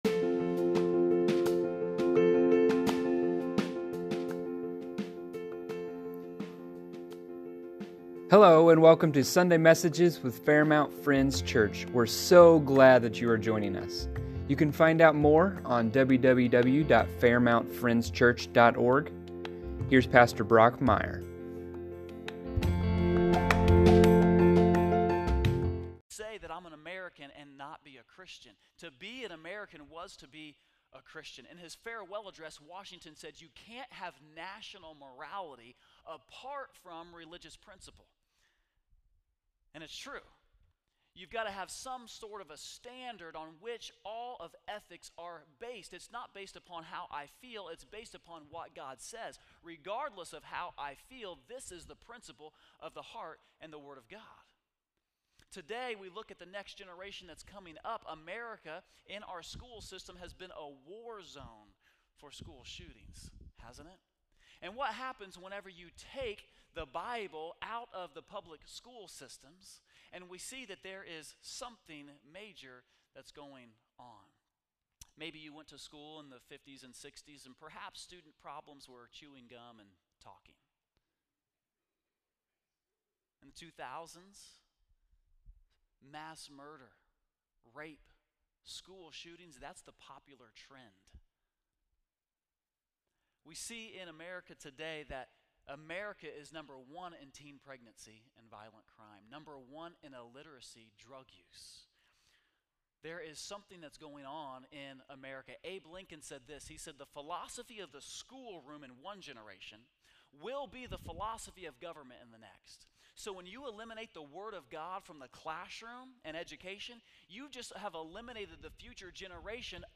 (We missed recording the first half of this message, unfortunately.)